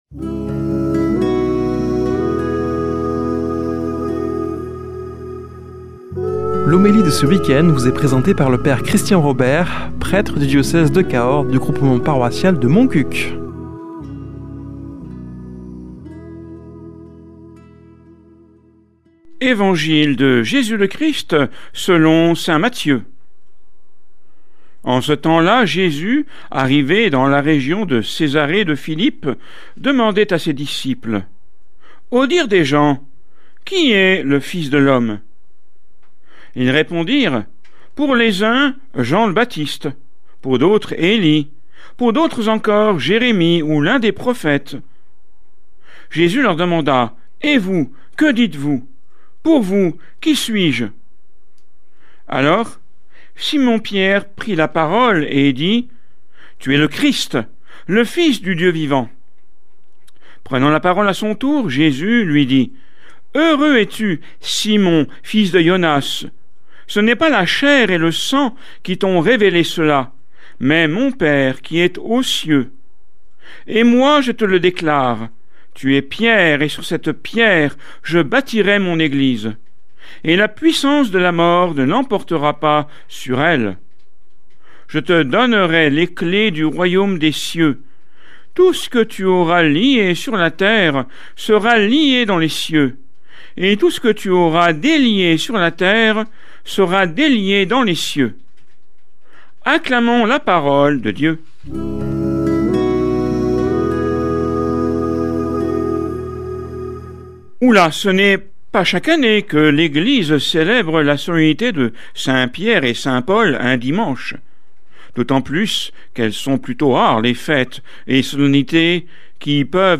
Homélie du 28 juin